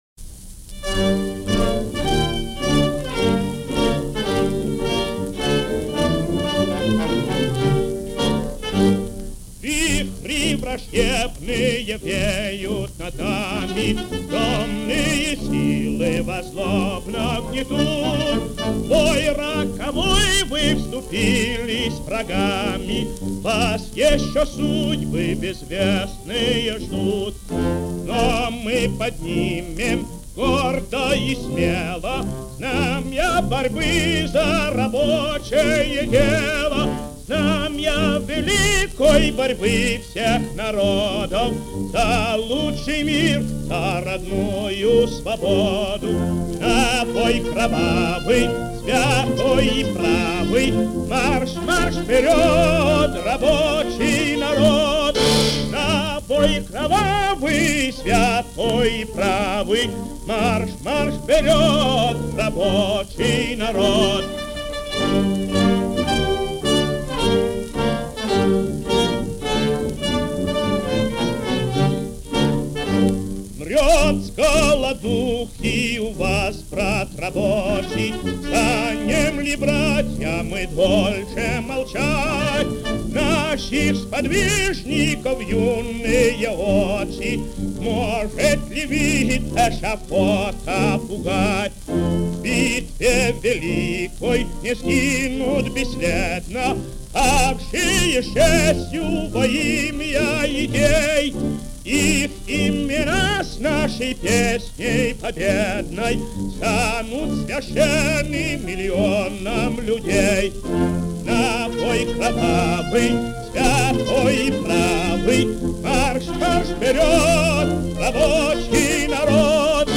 Для создания атмосферы этого дня редкое исполнение Варшавянки